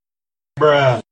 Bruh Sound Effect #2.mp3